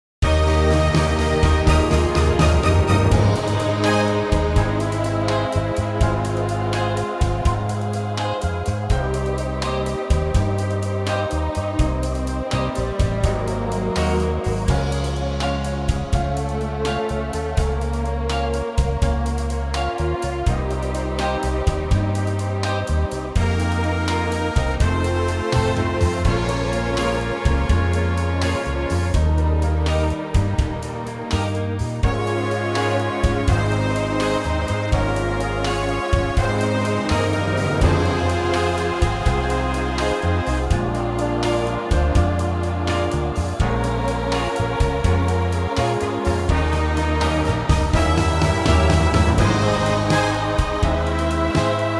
Unique Backing Tracks
key - Ab to A - vocal range - Bb to C# (optional E)